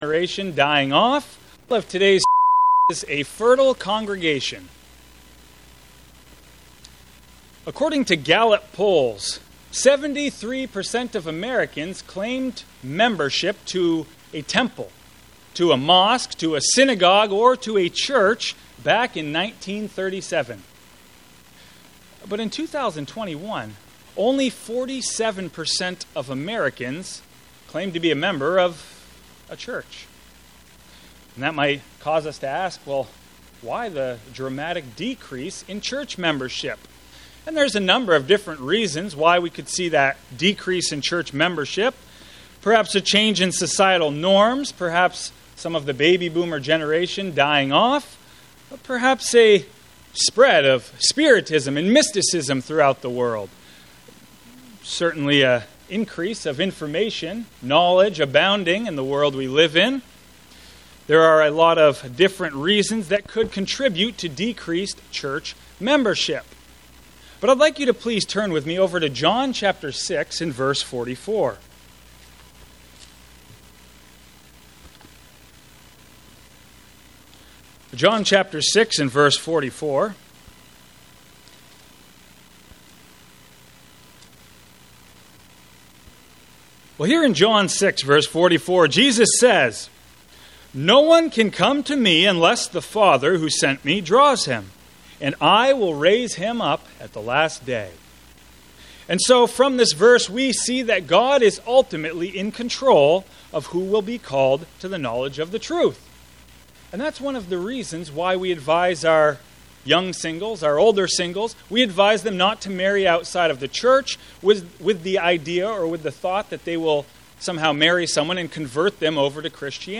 Sermons
Given in Fargo, ND